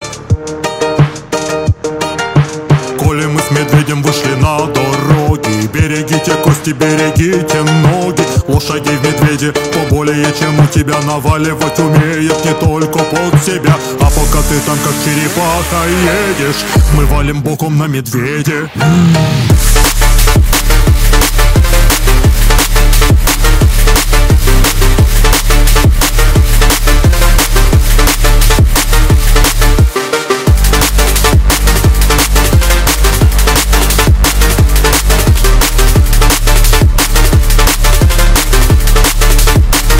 балалайка , драм энд бейс
дабстеп , нейрофанк